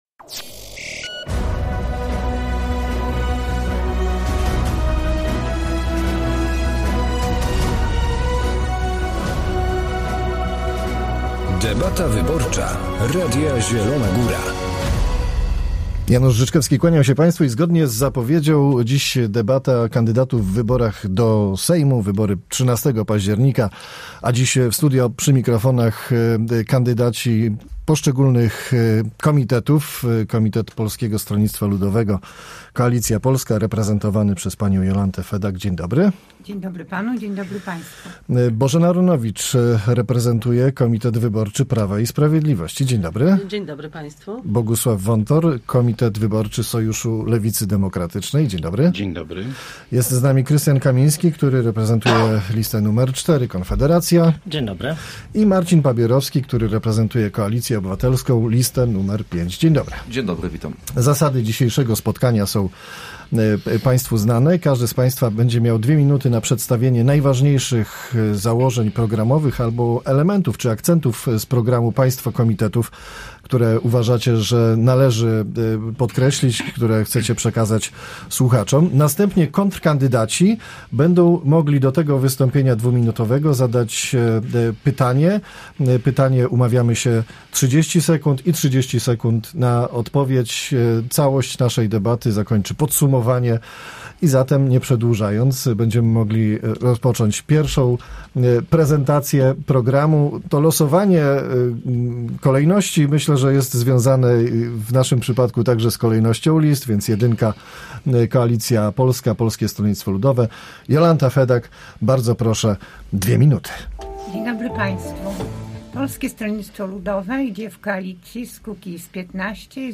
Debata wyborcza Radia Zielona Góra
13 października wybory parlamentarne. Dziś w Radio Zielona Góra pierwsza z debat z udziałem kandydatów do Sejmu.